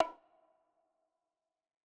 Tripp3 Perx 5 (Dro Konga 1).wav